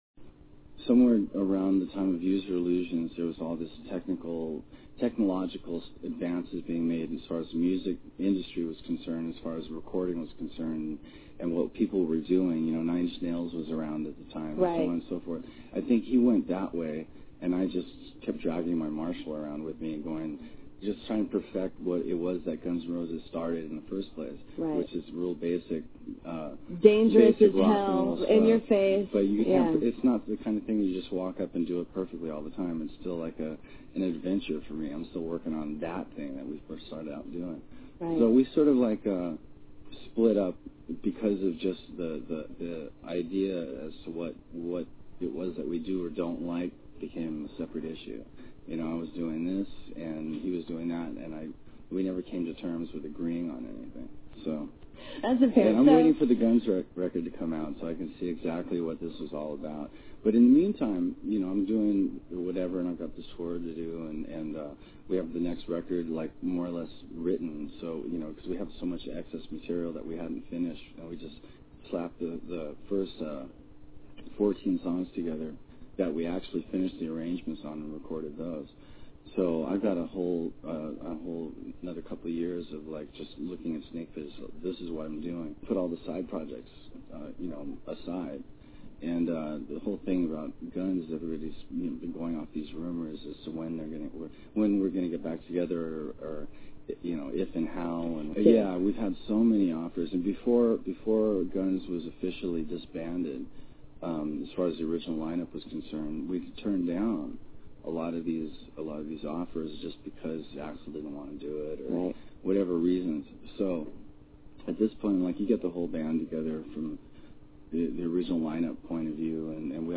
Soundbreak Interviews SLASH 7/26/00